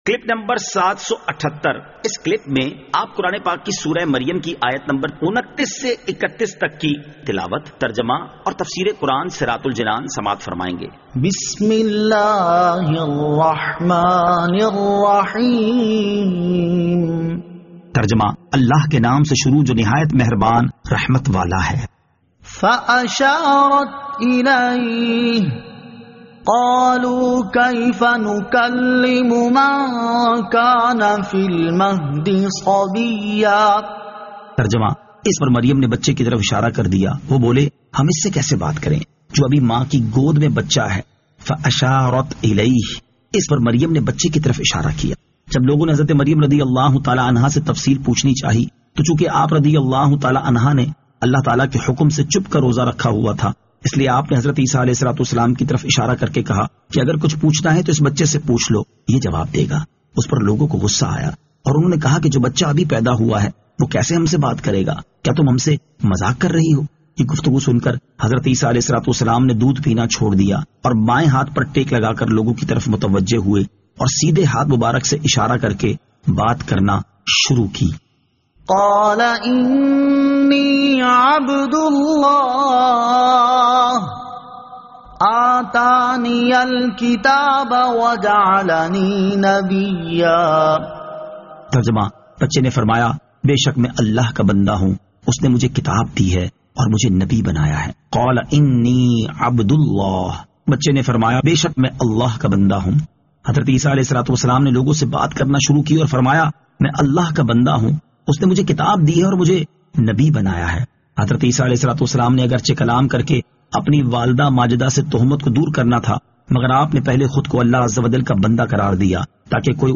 Surah Maryam Ayat 29 To 31 Tilawat , Tarjama , Tafseer